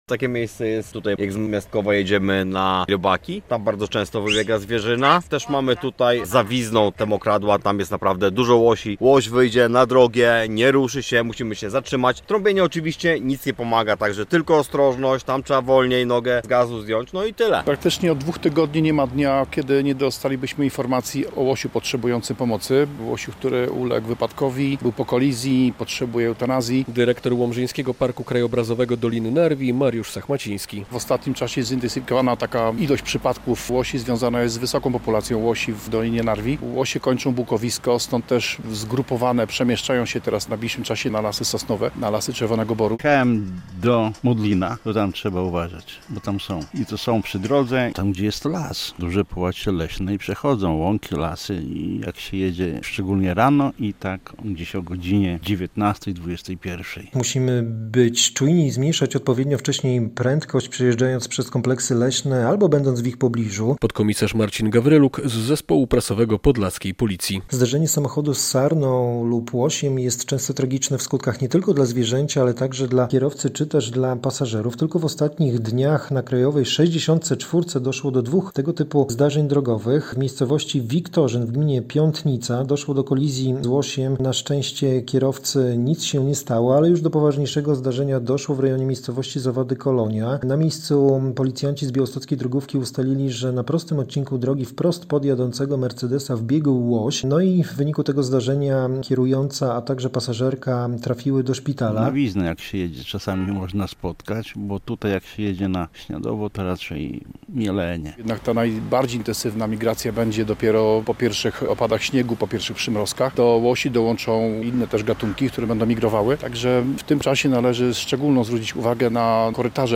Uwaga na łosie! - relacja